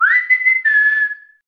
10-diverse-sms-töne